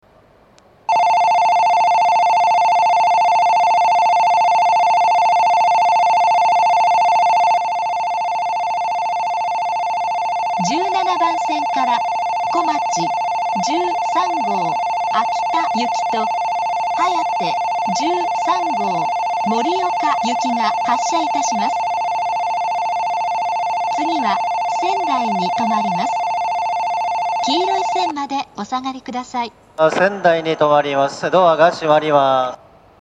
標準的な音程の発車ベルを使用していますが、１５・１６番線の発車ベルは音程が低いです。
１７番線発車ベル こまち１３号秋田行とはやて１３号盛岡行の放送です。